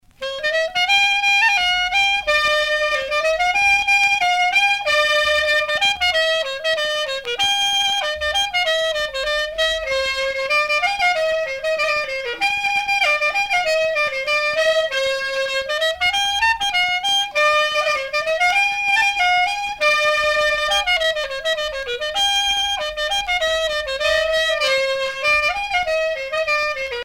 danse : scottich trois pas
Sonneurs de clarinette en Bretagne
Pièce musicale éditée